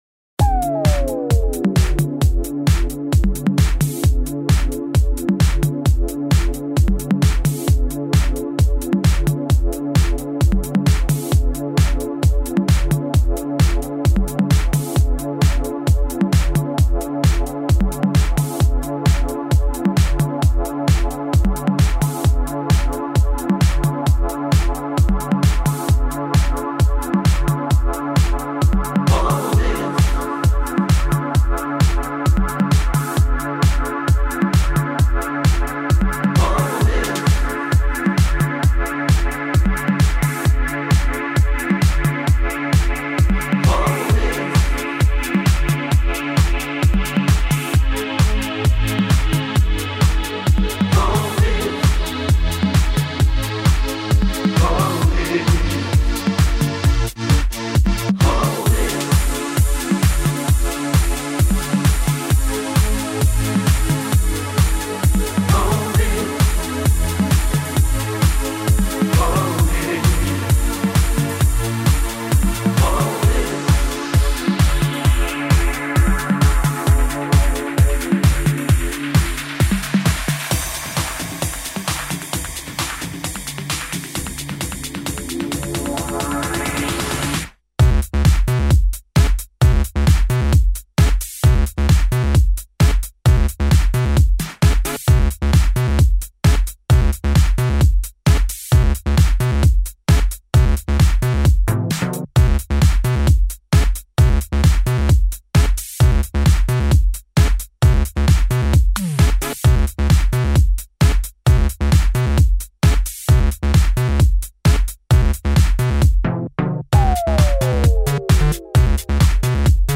a darker, dirtier house sound